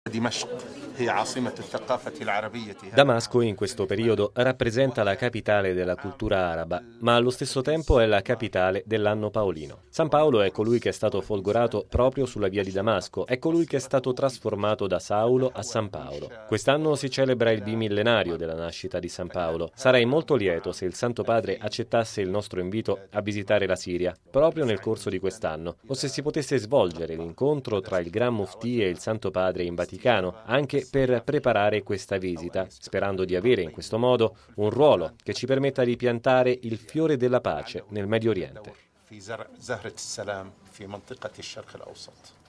Il Gran Muftì di Siria, Ahmed Badr Al Deem Hassun, parla della straordinaria esperienza dell’Anno Paolino in corso e invita il Papa in Siria